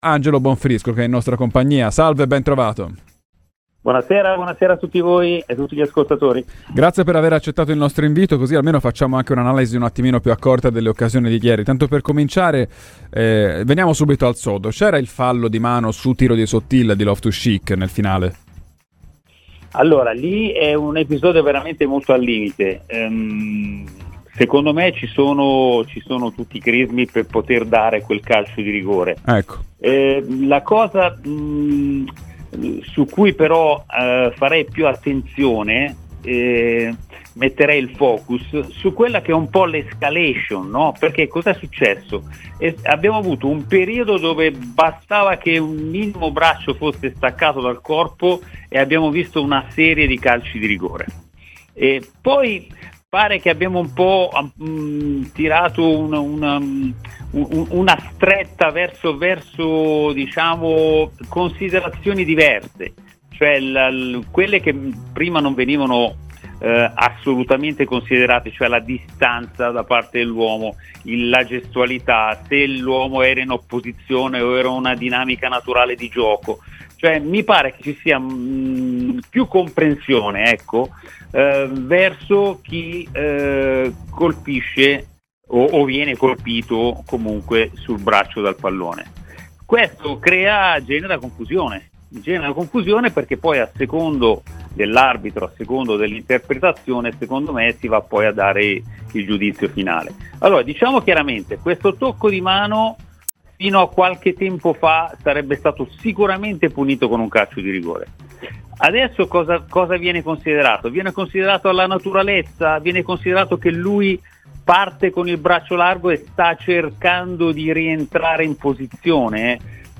L'ex arbitro